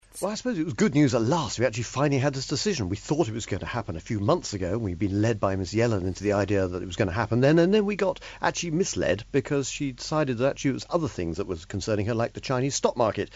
【英音模仿秀】美联储加息市场反应未如预期 听力文件下载—在线英语听力室